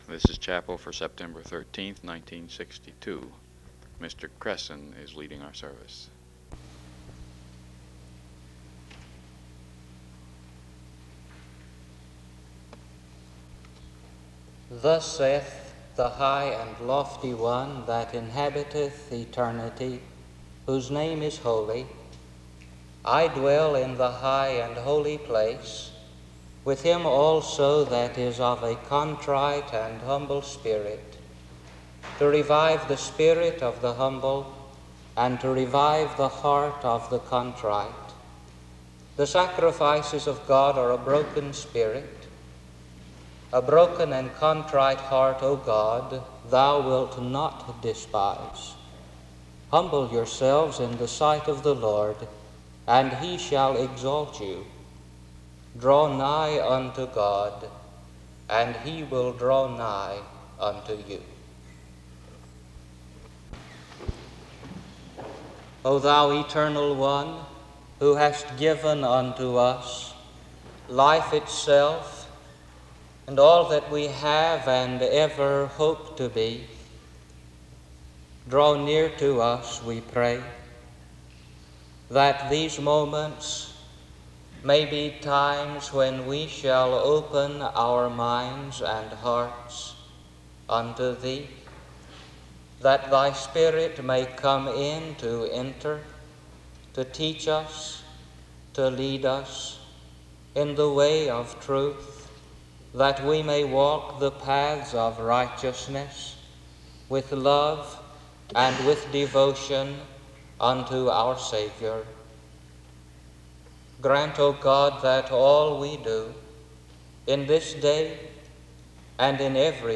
The service begins with a scripture reading and prayer from 0:14-2:19.
SEBTS Chapel and Special Event Recordings SEBTS Chapel and Special Event Recordings